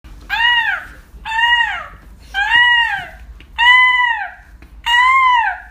Seagull 4.wav